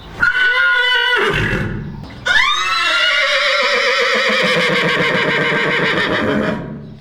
Horse whinny
A horse’s whinny starts with a high frequency tone, followed by a lower one